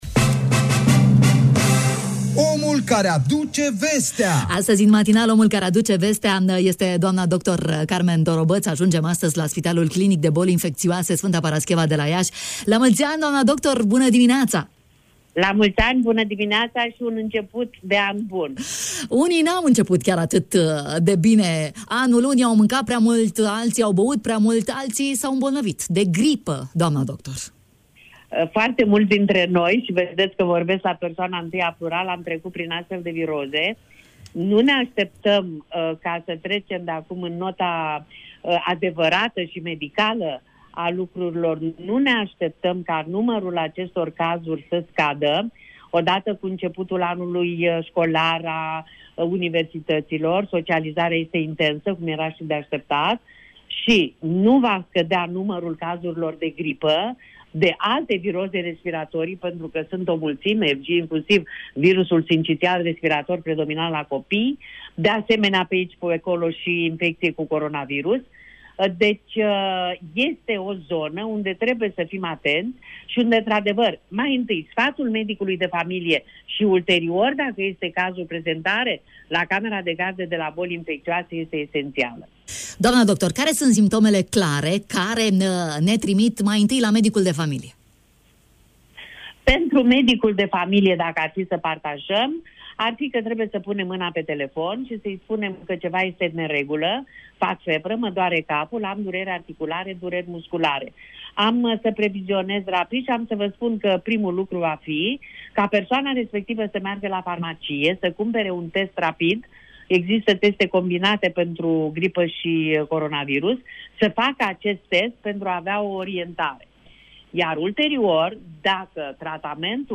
ne-a adus mai multe recomandări în matinalul Radio România Iași